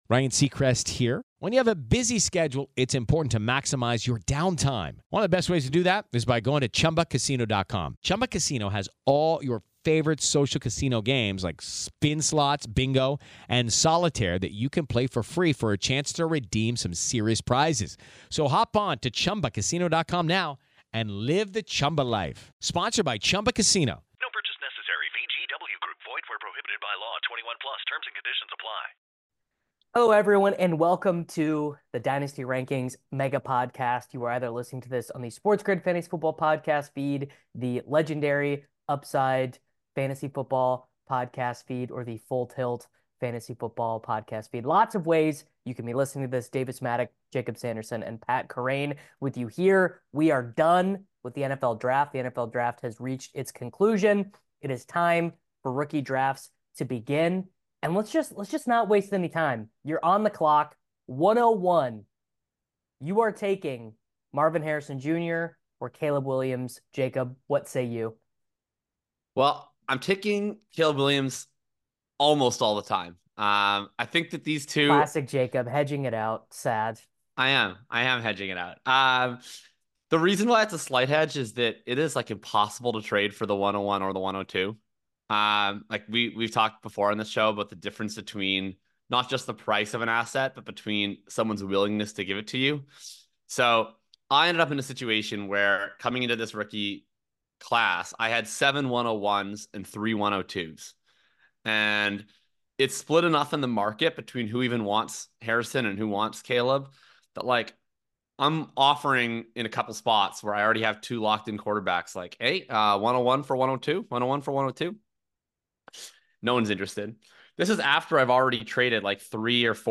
Dynasty Rookie Rankings Roundtable w